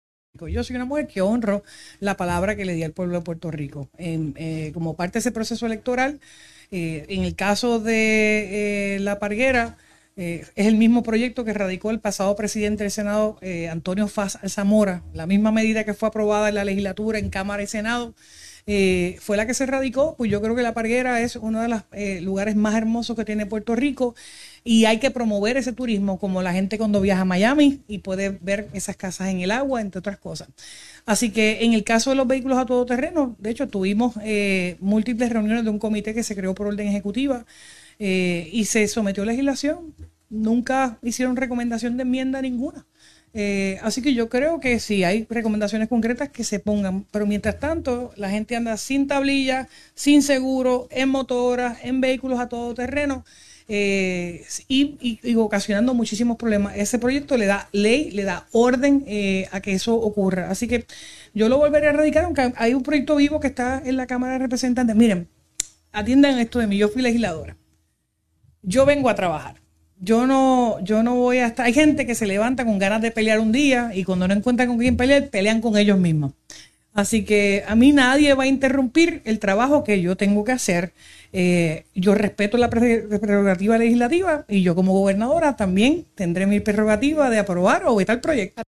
A pesar de que las medidas para otorgar títulos de propiedad a las casas en La Parguera y de permitir que los vehículos todoterreno puedan transitar por avenidas y calles de Puerto Rico recibieron informes negativos en el Senado, la gobernadora Jenniffer González dijo que radicará nuevamente proyectos sobre el particular.